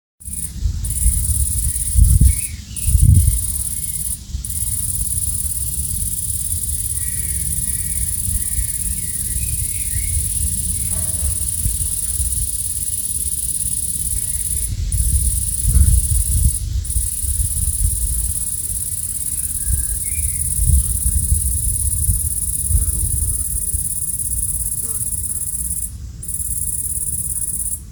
Grünes_Heupferdchen-.mp3